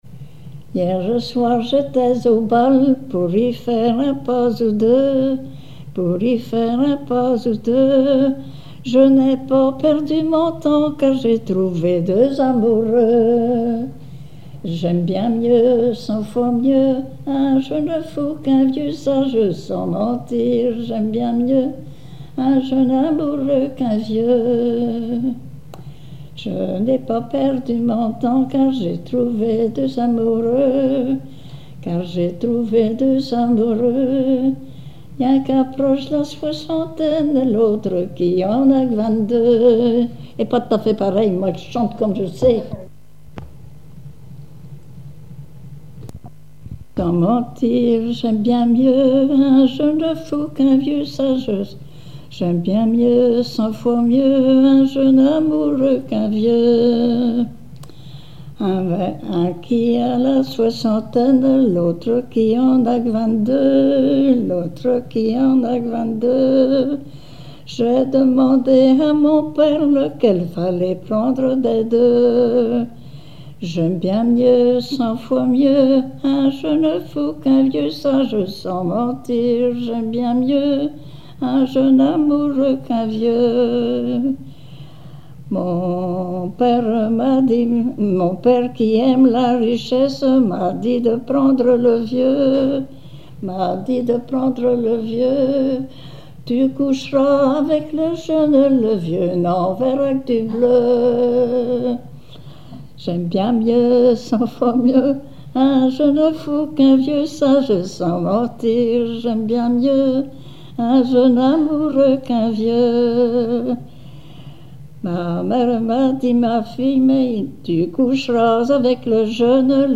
Chansons en dansant
Genre laisse
Chansons et témoignages
Pièce musicale inédite